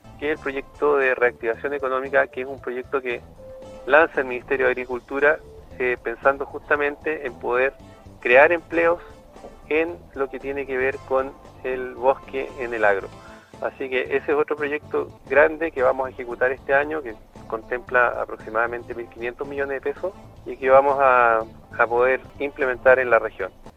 El Director Regional de Conaf, Jorge Aichele, confirmó a Radio Sago que, por medio del Ministerio de Agricultura, se está proyectando un programa de reactivación económica relacionada con trabajos en el bosque.